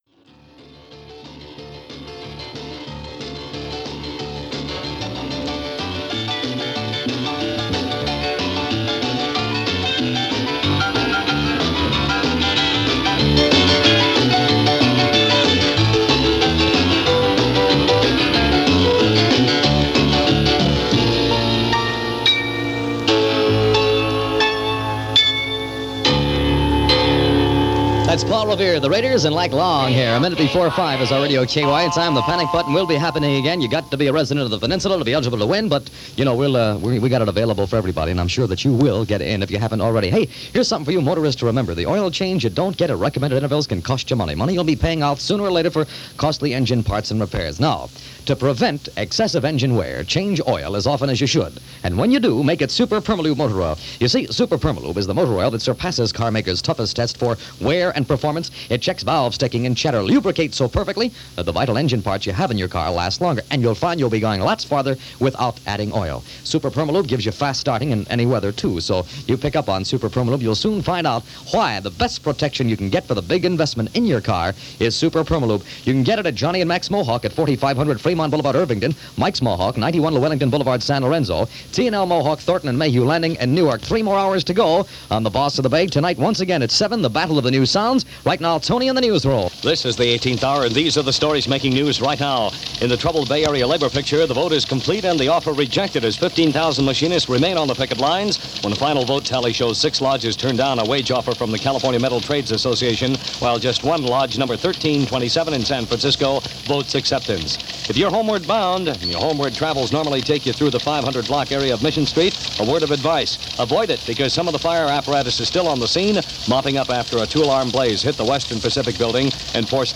Radio in Top-40's heyday.